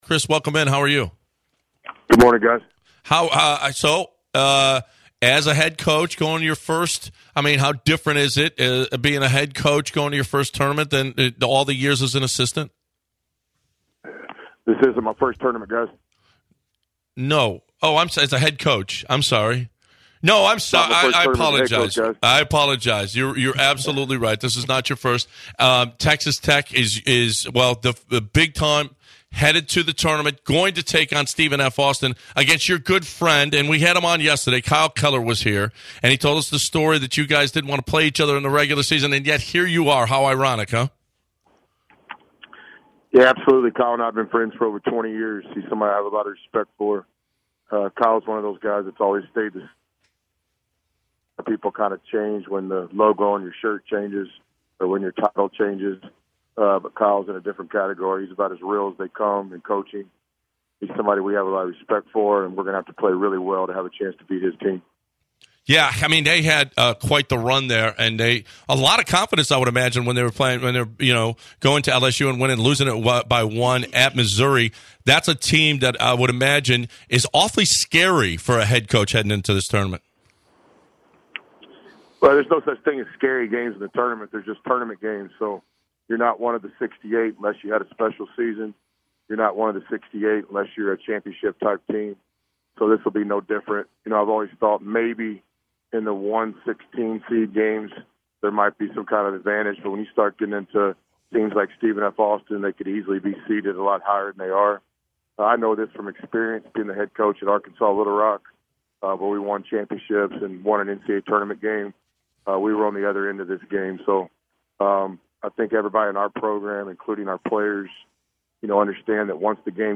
The guys interview Texas Tech head coach Chris Beard and get his thoughts on the upcoming NCAA tournament.